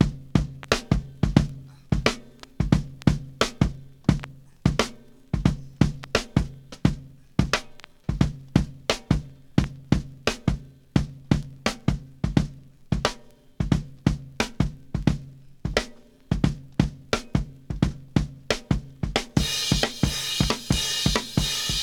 • 88 Bpm HQ Rock Drum Groove G Key.wav
Free drum groove - kick tuned to the G note. Loudest frequency: 1442Hz
88-bpm-hq-rock-drum-groove-g-key-H83.wav